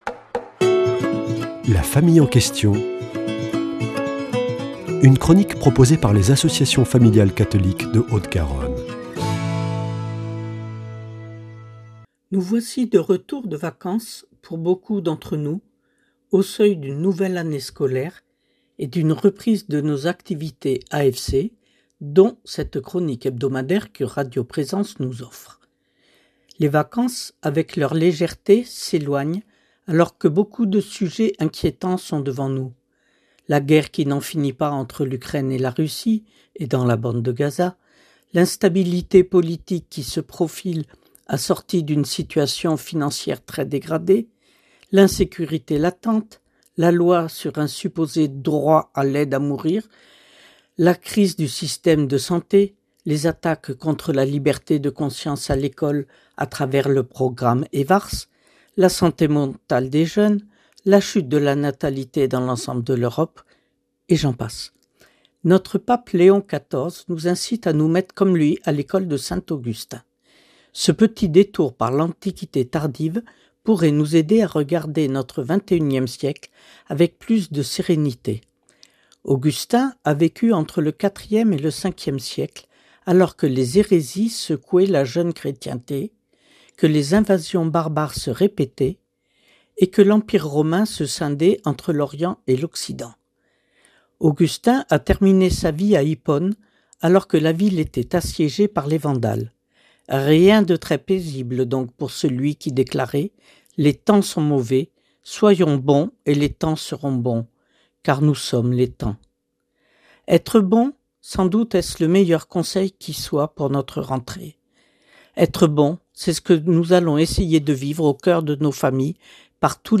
mercredi 10 septembre 2025 Chronique La famille en question Durée 3 min